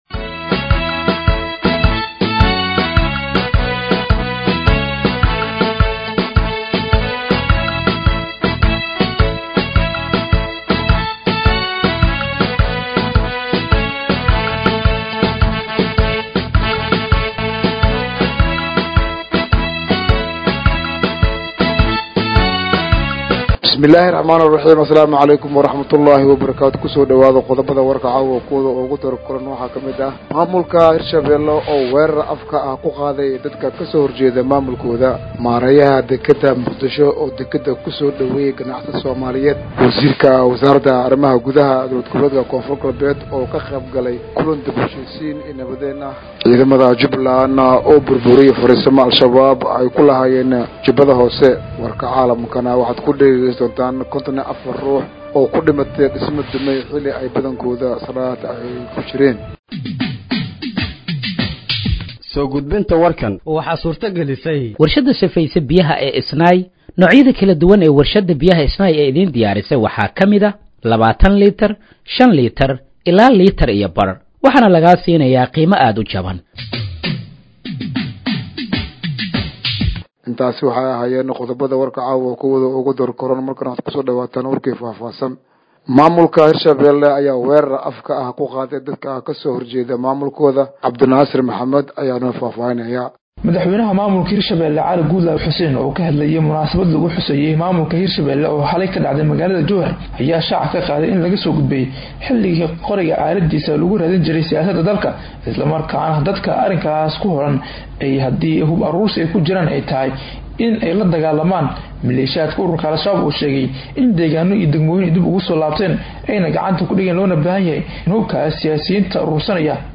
Dhageeyso Warka Habeenimo ee Radiojowhar 06/10/2025